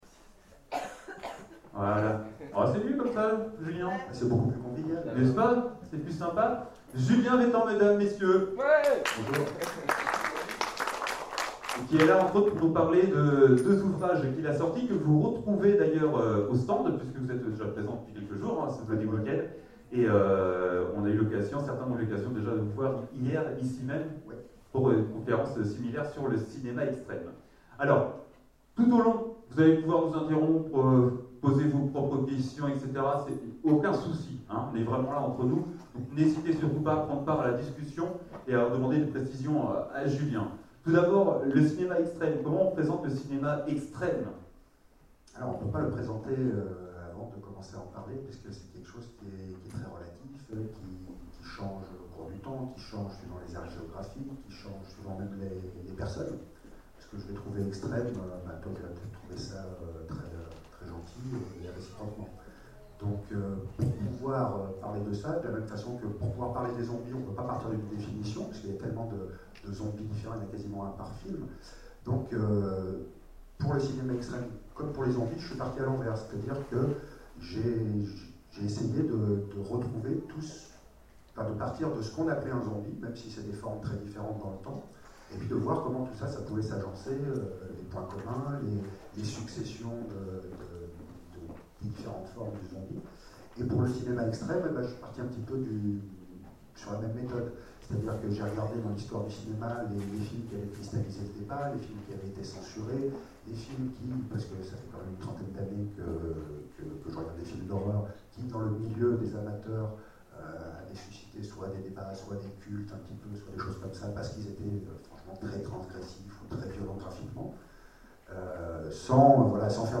Bloody Week end 2013 : Conférence Extrême !